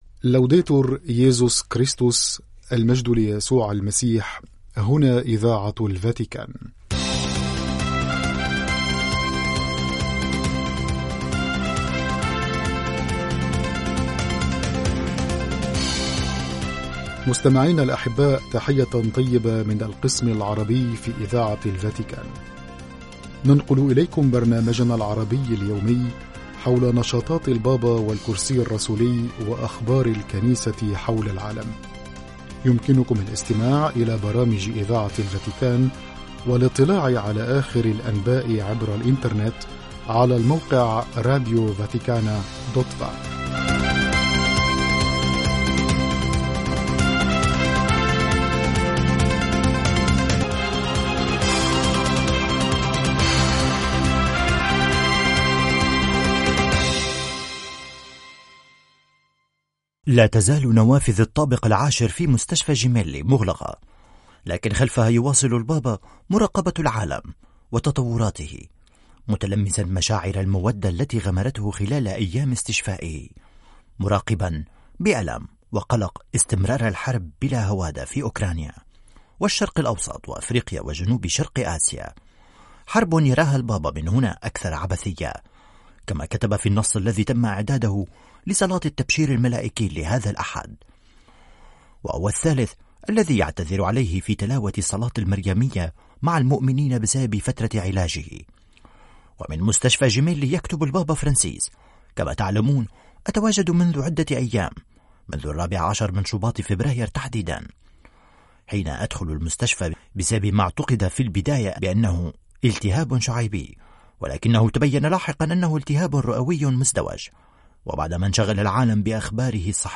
أخبار